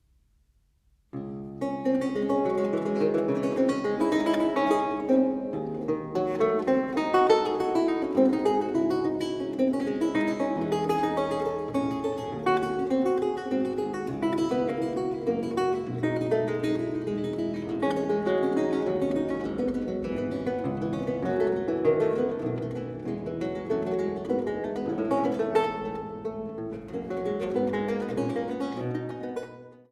Laute